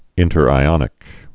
(ĭntər-ī-ŏnĭk)